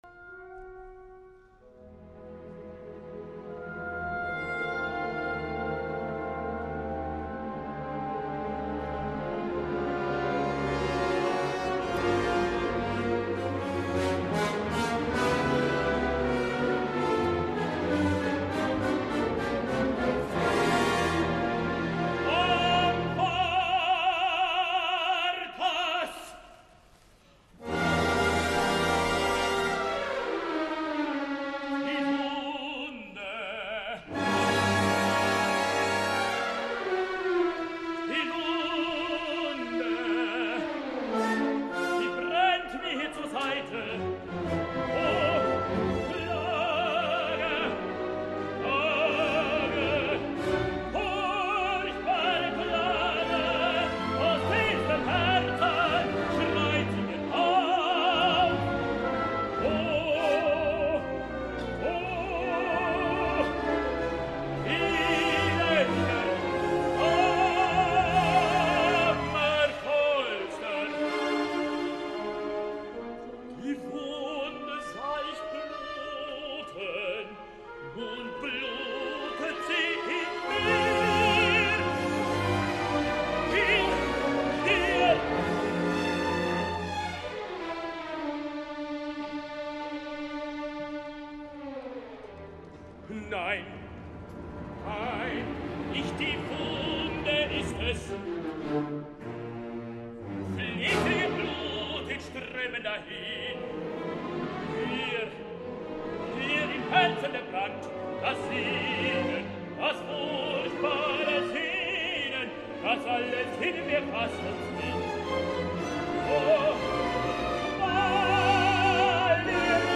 El nivell és correcta, però com sempre en el Bayreuth de fa masses anys, millorable. Un gran Gurnemanz, Kwangchul Youn, i poca cosa més.